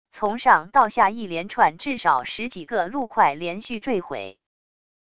从上到下一连串至少十几个陆块连续坠毁wav音频